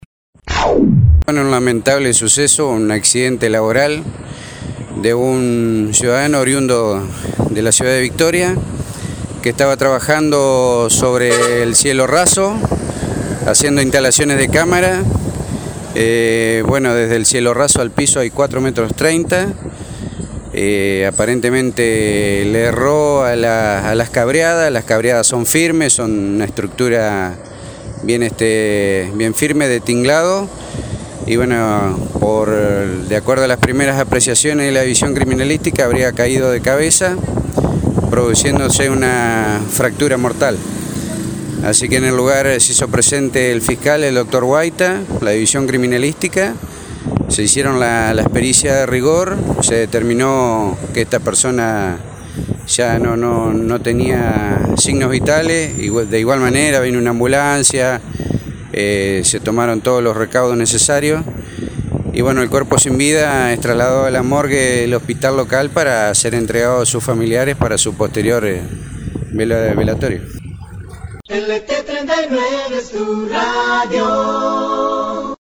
Jefe Policía Departamental Victoria – Omar Rigondi